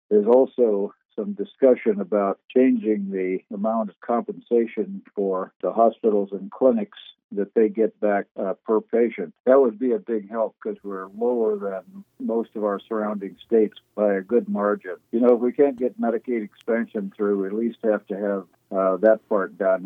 State Rep. Mike Dodson spoke on the issue during KMAN’s weekly legislative update Friday morning.